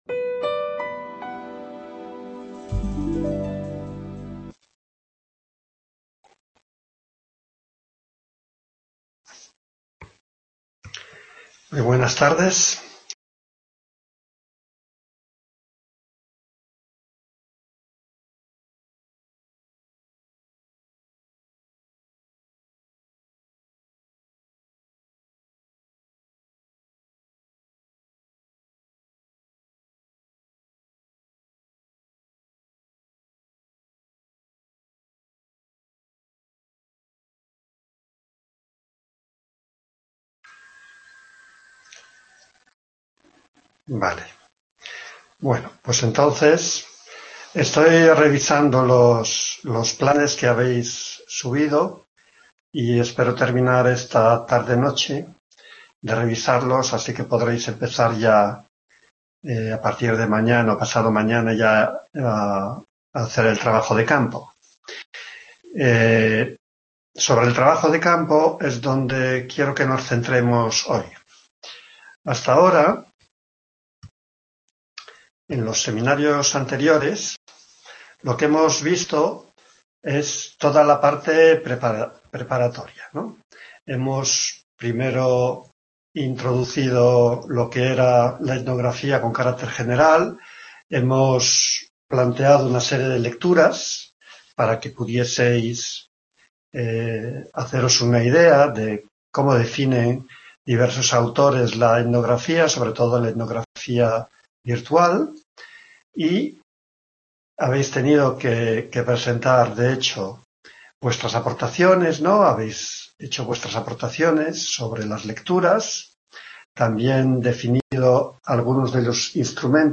El trabajo de campo. Nota: el seminario fue grabado en dos sesiones contiguada unida en un único documento.